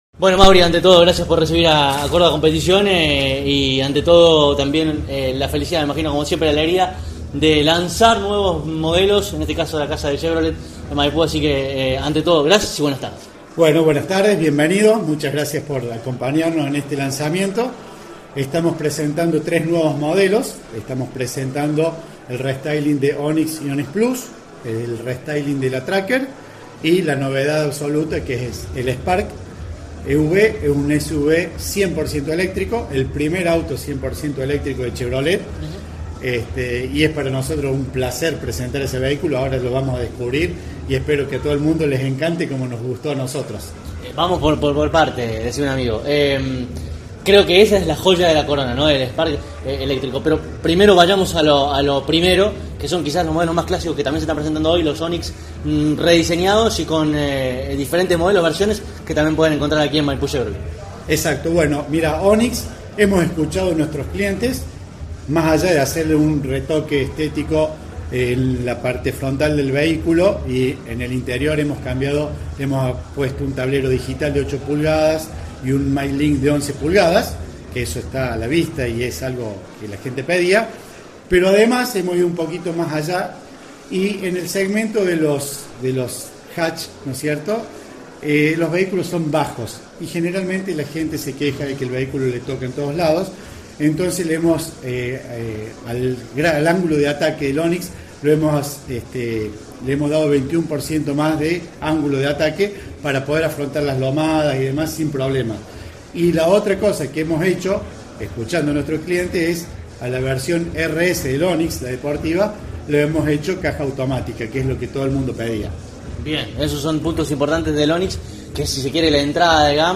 En la acción de presentación denominada «Chevrolet 360» que realizó Maipú Chevrolet, en Avenida Colón al 4125, se lanzaron oficialmente la renovación de los modelos Onix, Tracker junto con la gran novedad del momento: el flamante Spark EUV, el revolucionario primer SUV 100% eléctrico de GM.